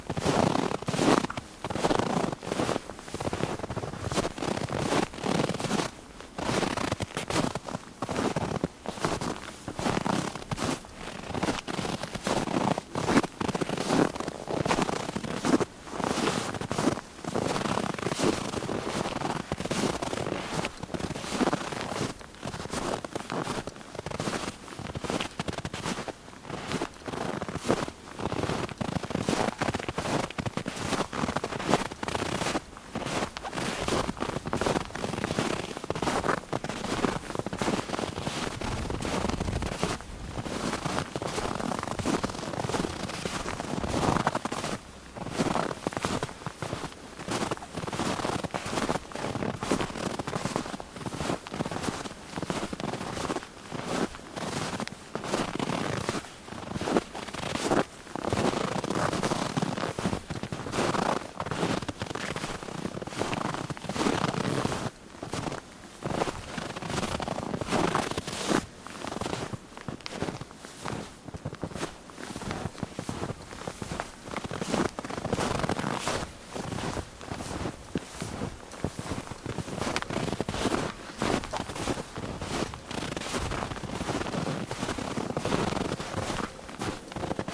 随机 " 脚步声 靴子 深雪中行走 缓慢的嘎吱嘎吱的声音
描述：脚步靴深雪步行慢嘎吱嘎吱嘎吱嘎吱
Tag: 脚步 靴子 脆脆的 吱吱作响